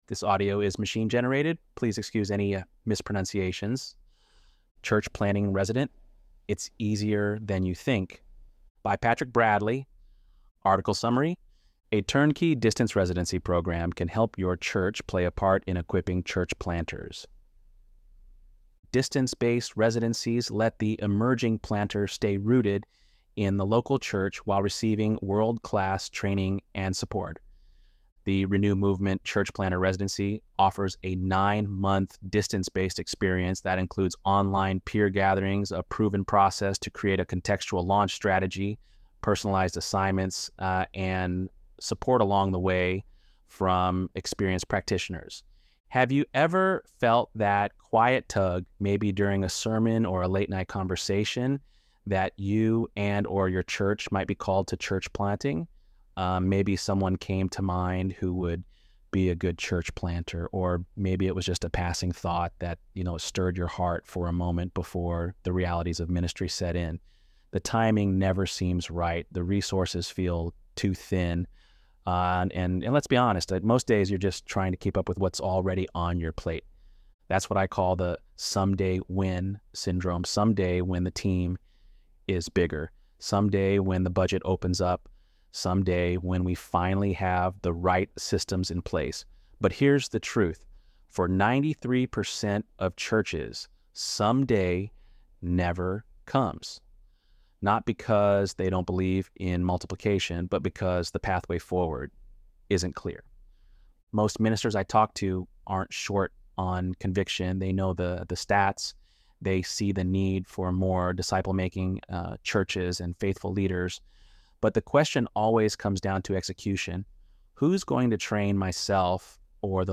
ElevenLabs_6.16_Church_Plant.mp3